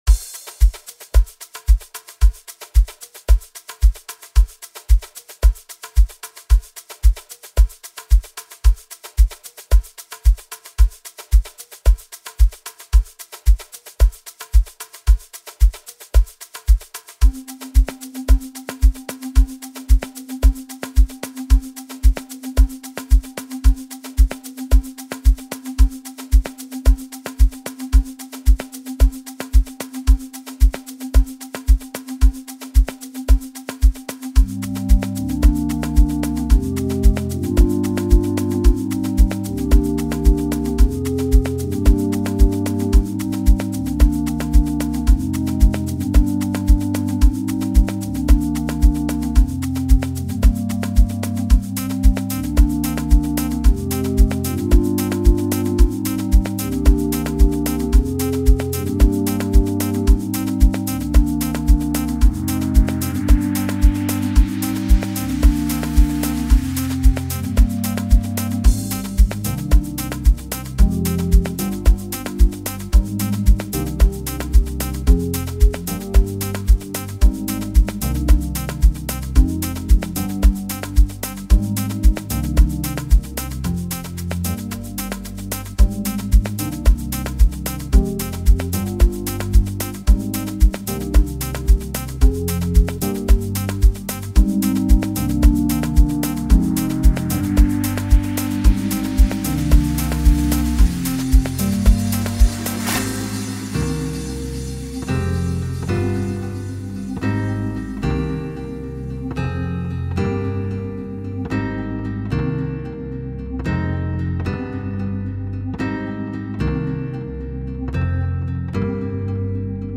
very beautiful and lush Private School Production